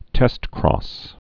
(tĕstkrôs, -krŏs)